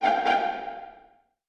ihob/Assets/Extensions/CartoonGamesSoundEffects/Suspicious_v1/Suspicious_v5_wav.wav at master
Suspicious_v5_wav.wav